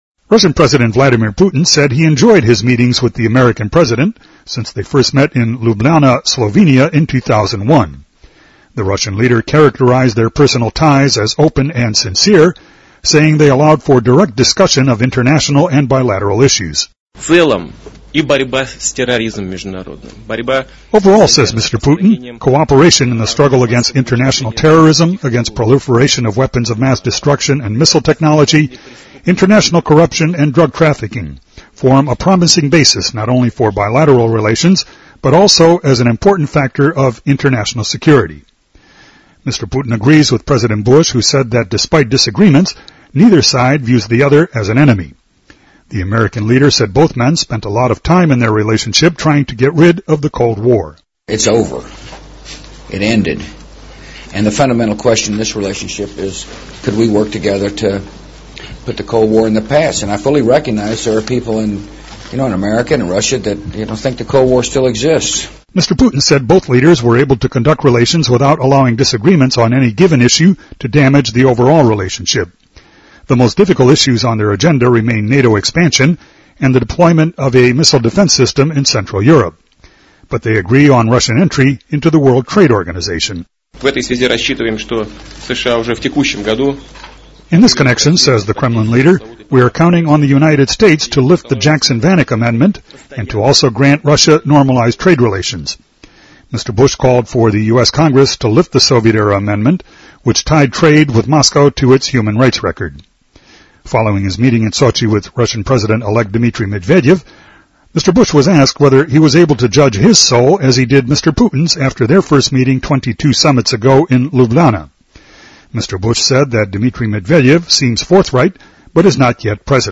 布什与普京回顾美俄七年来的关系|英语新闻听力
News